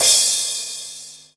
Crashes & Cymbals
CRASH099.WAV